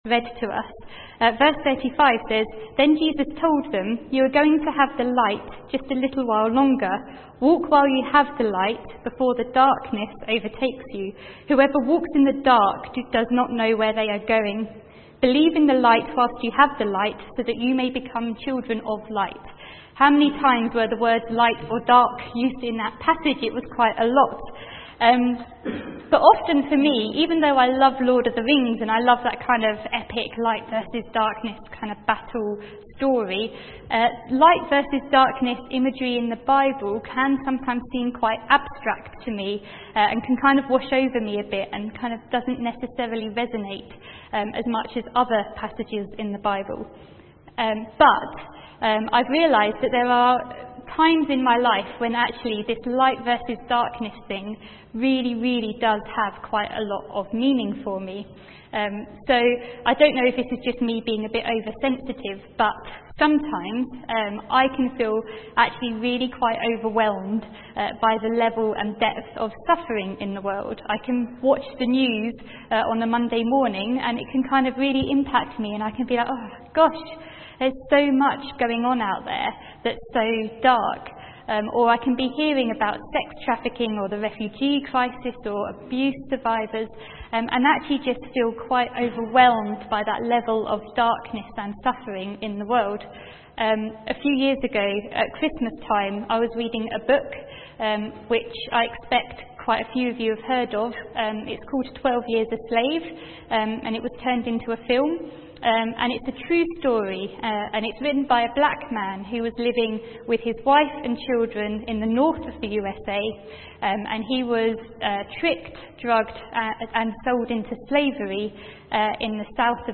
From Service: "10.45am Service"